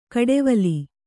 ♪ kaḍevali